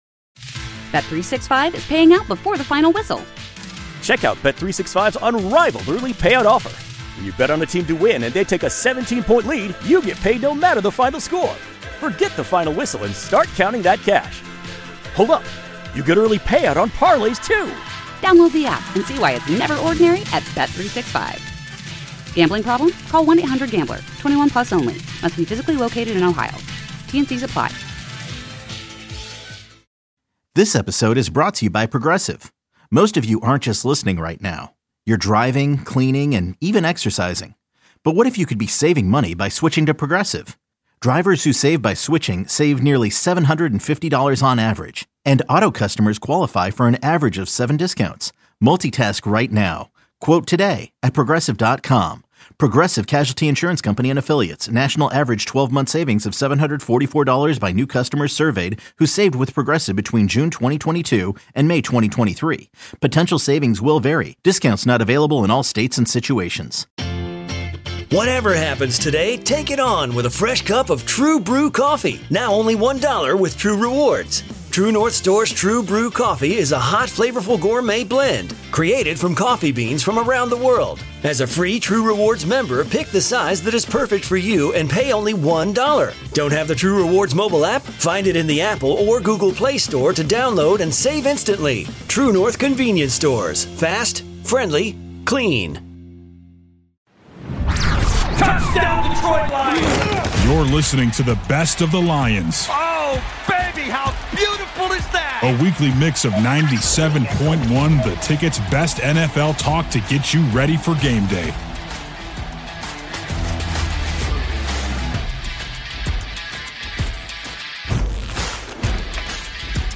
Best of Lions on 97.1 The Ticket: Monday reactions to win vs. Commanders. 11/10/25 - Victory Monday is back! Monday OverreactJims, Was Sunday more about Campbell or the Commanders? T.J. Lang in-studio.